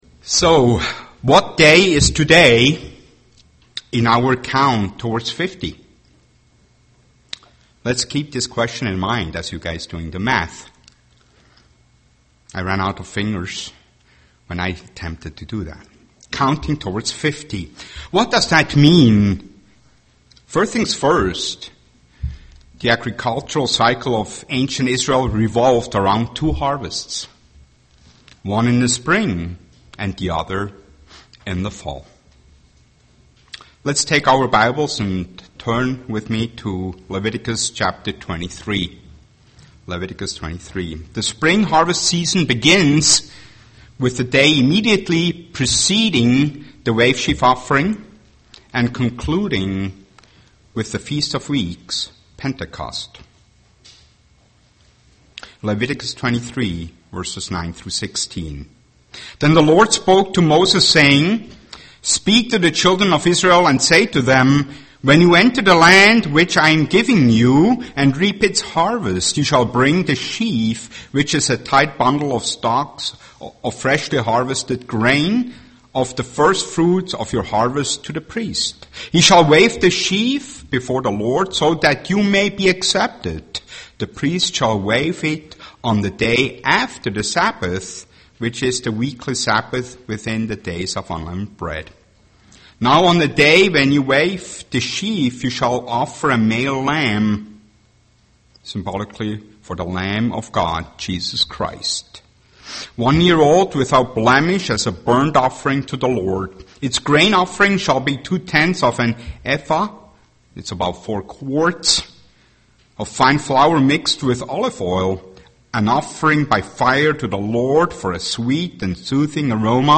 Given in Twin Cities, MN
UCG Sermon wave sheaf wave sheaf offering feast of weeks Pentecost God's Promises Studying the bible?